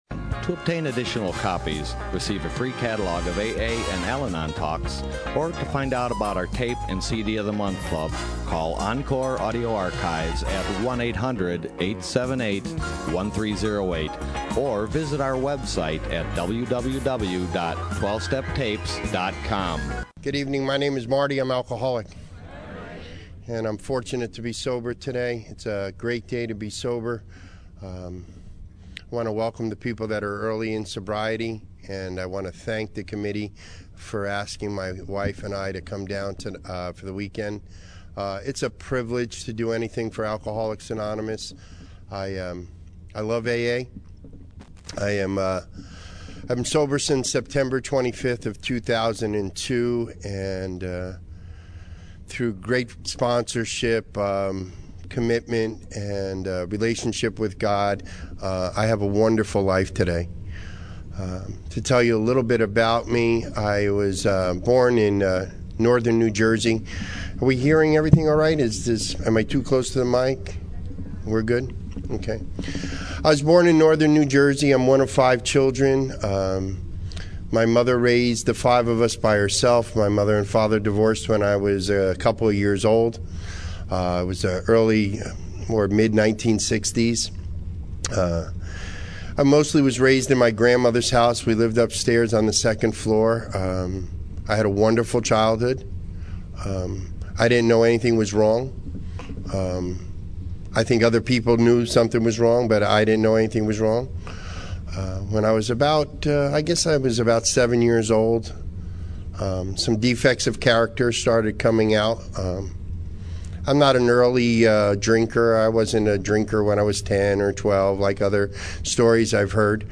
Encore Audio Archives - 12 Step Recovery Laughlin Roundup 2014